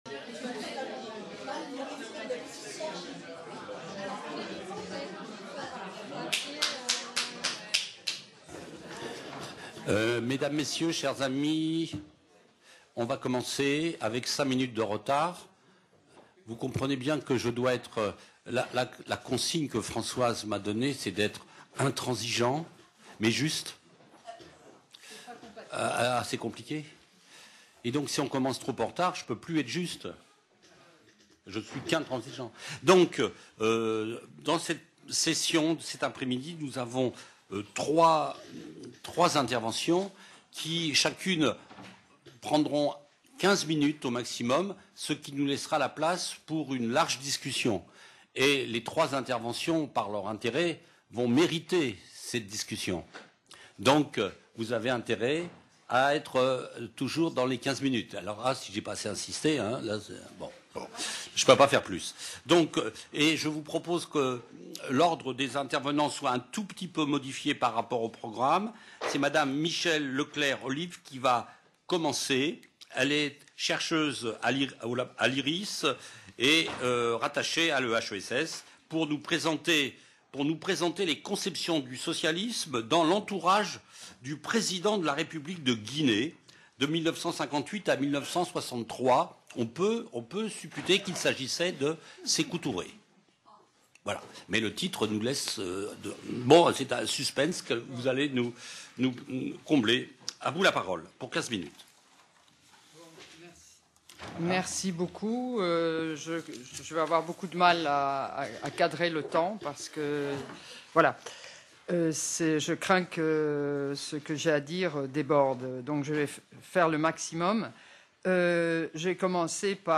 Table-ronde : Témoins | Canal U